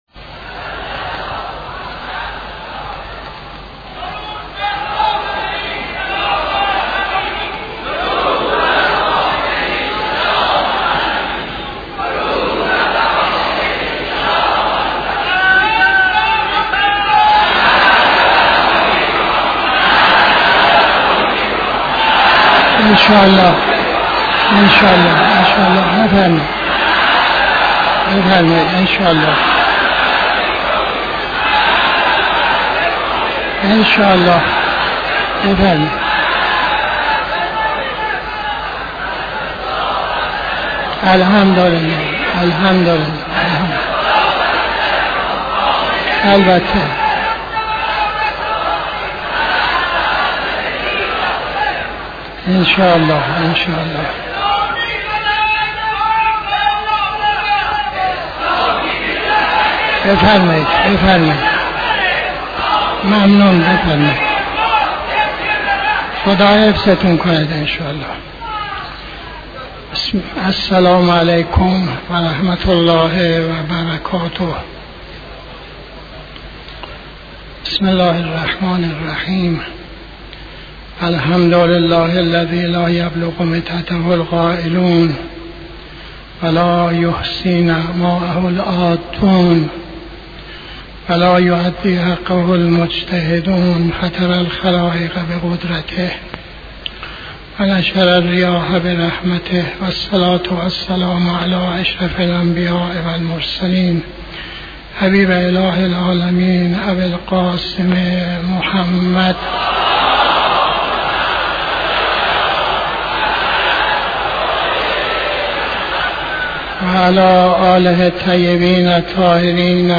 خطبه اول نماز جمعه 11-10-83